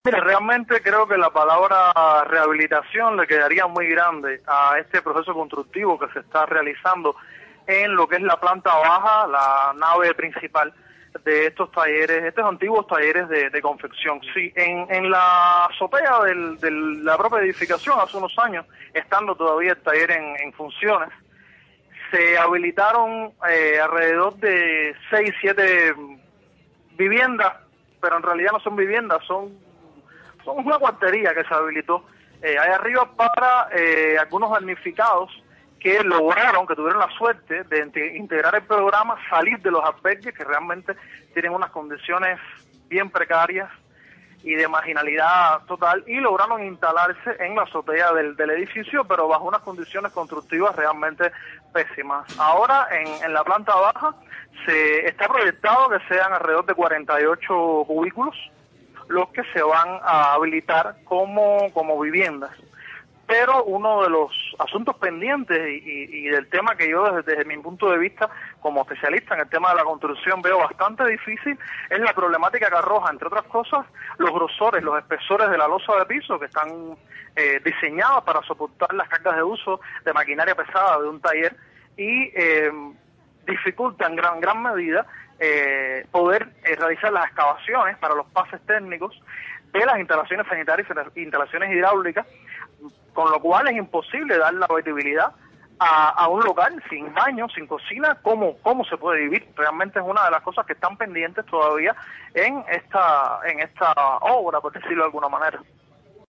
El reportero dijo en el programa Cuba al Día, de Radio Martí, que en los últimos días recorrió el área donde está enclavado un viejo taller en la barriada de Lawton, del municipio habanero 10 de Octubre, que ha sido destinado para la "reubicación" de familias que antes vivían en albergues.